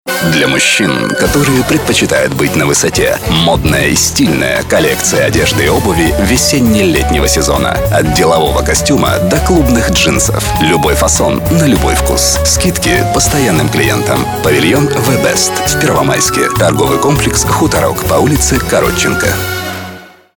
РЕКЛАМА НА РАДИО
Информационный радиоролик - предоставленные клиентом сведения, прочитанные под музыку или в сопровождении спецэффектов.